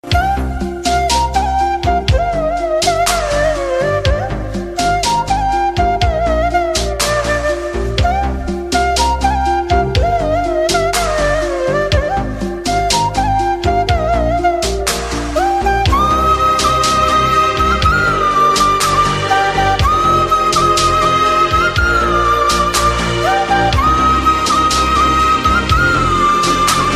Bansuri Ringtones Flute Ringtones Instrumental Ringtones